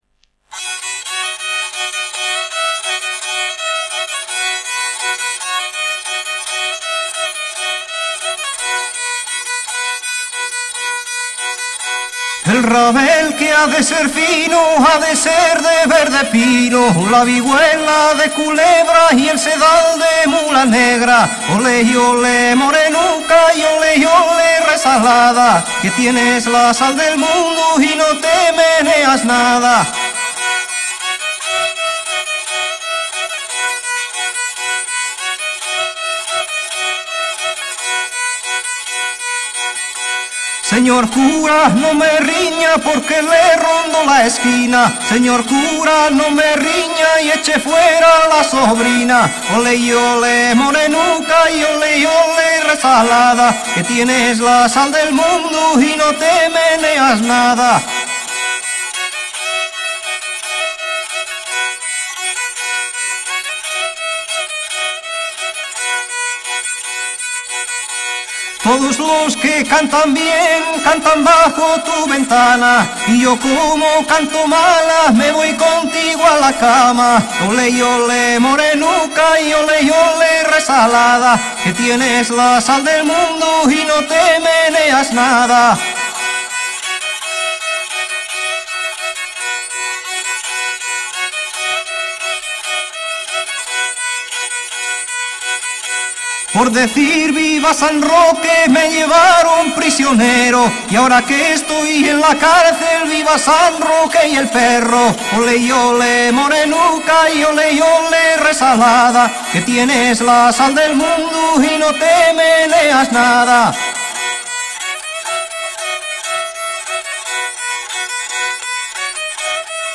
Rabel, zanfona y sonajas